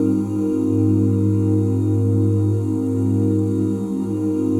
OOH G#MAJ9.wav